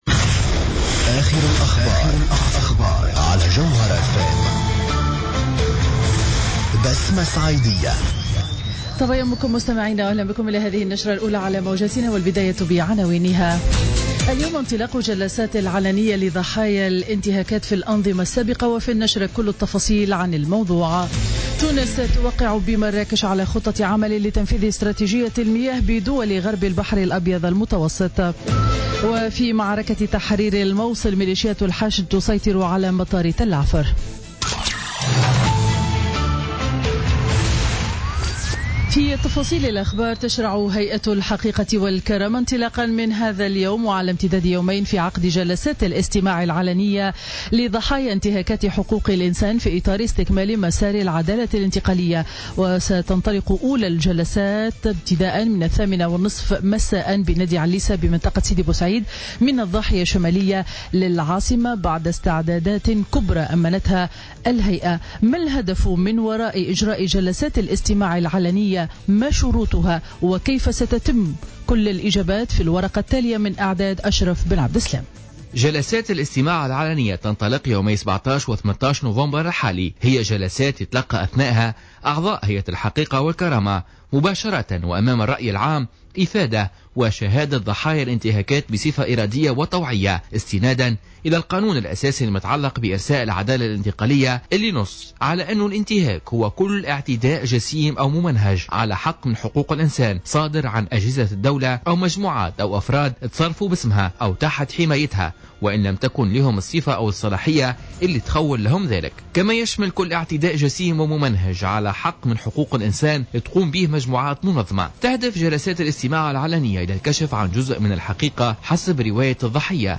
نشرة أخبار السابعة صباحا ليوم الخميس 17 نوفمبر 2016